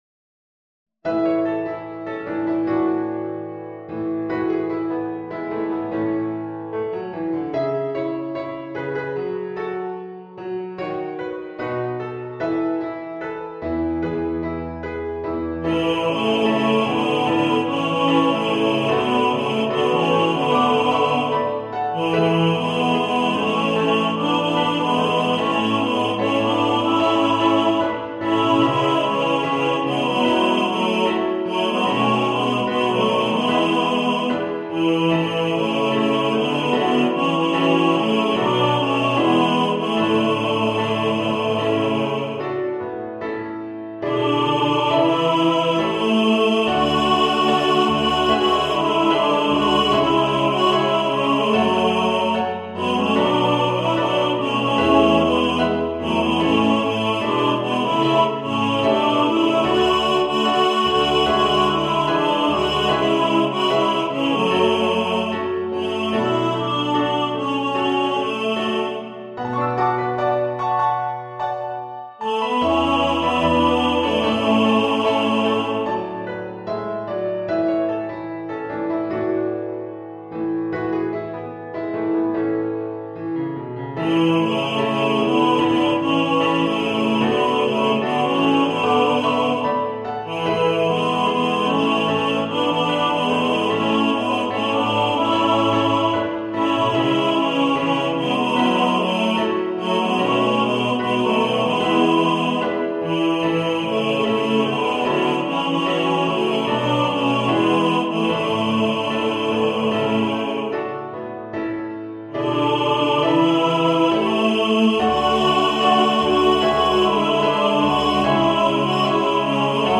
Ein modernes Chorarrangement im Stil von Udo Jürgens.